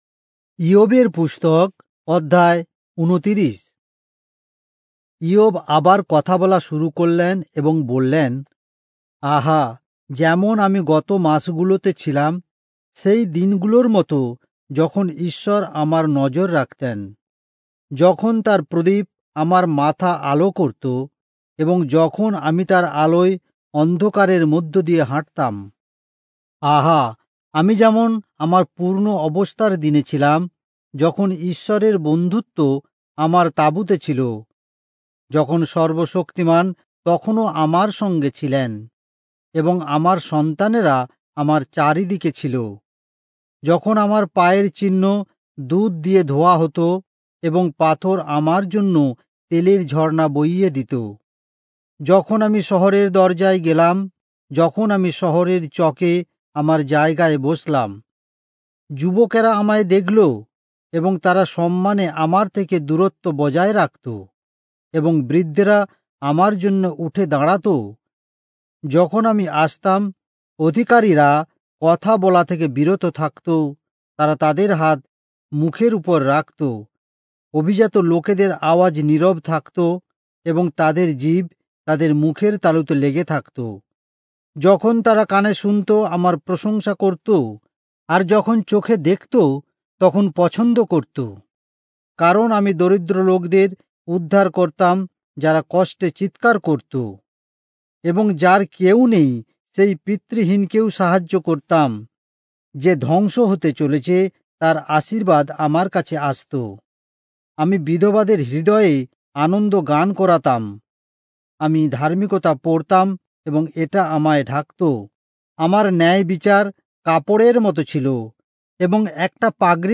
Bengali Audio Bible - Job 87 in Irvbn bible version